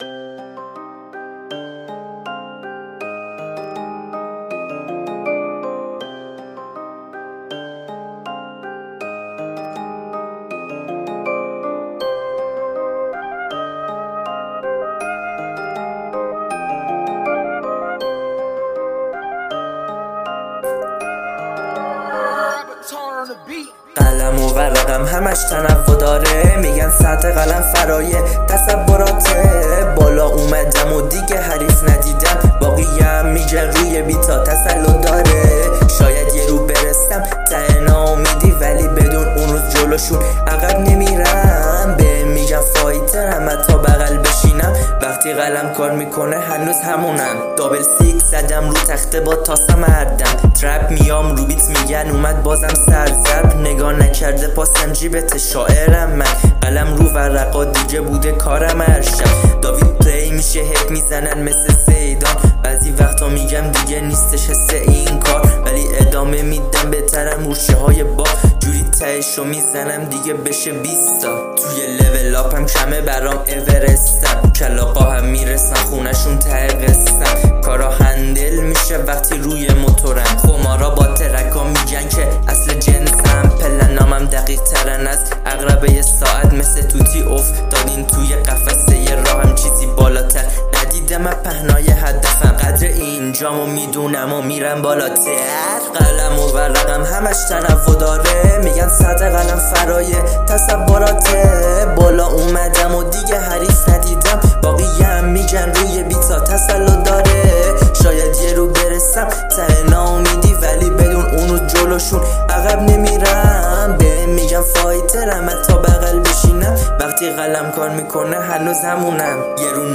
نیواسکول
دوستان این اولین باره اینطوری میخونم حتما نظرتونو بگید